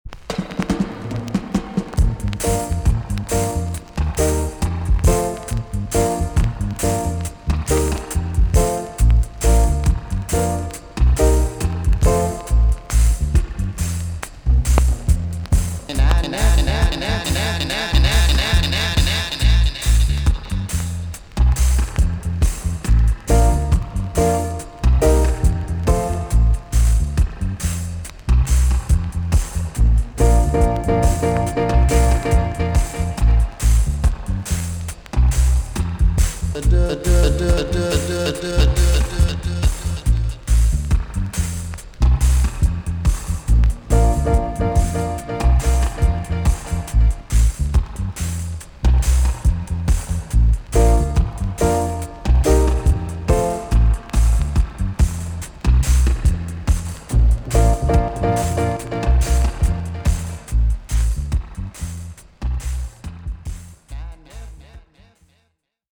TOP >REGGAE & ROOTS
EX-~VG+ 少し軽いチリノイズがあります。